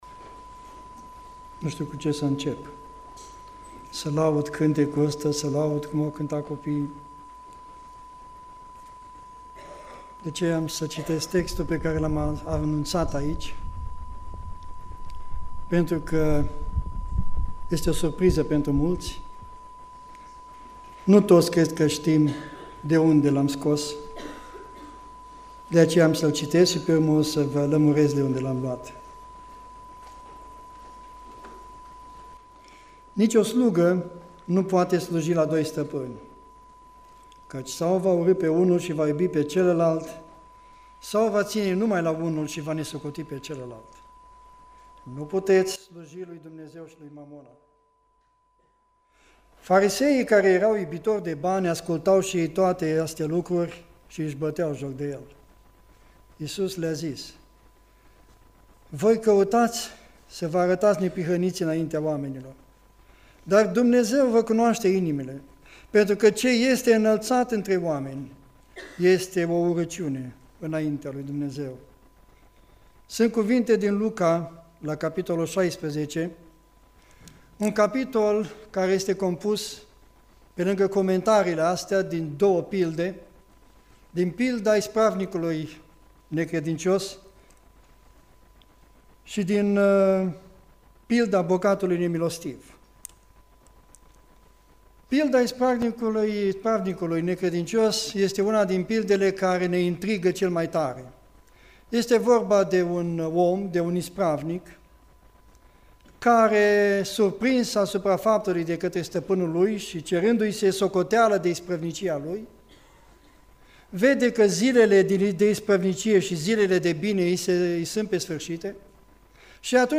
Predica Aplicatie 1 Timotei 6